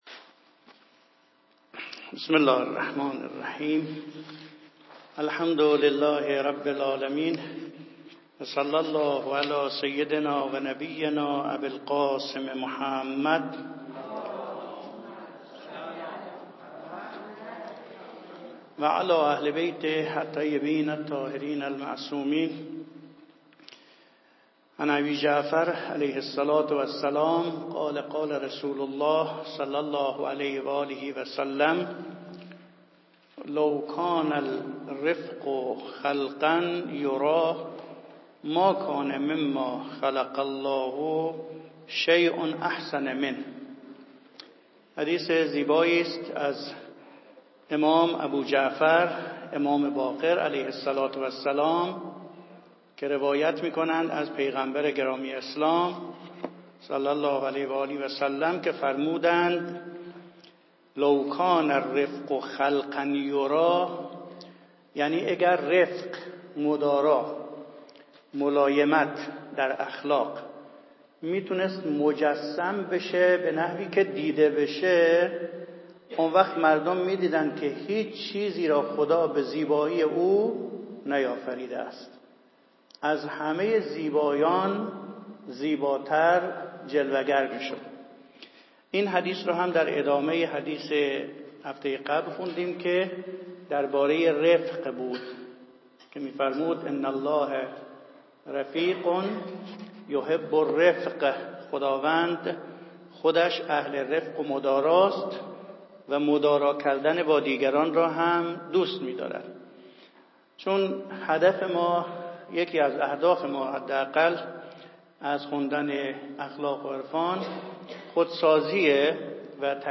دروس شفاهی عرفان اسلامی